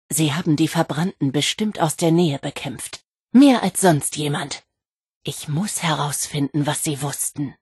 Wastelanders: Audiodialoge
Beschreibung Lizenz Diese Datei wurde in dem Video-Spiel Fallout 76 aufgenommen oder stammt von Webseiten, die erstellt und im Besitz von Bethesda Softworks sind, deren Urheberrecht von Bethesda Softworks beansprucht wird.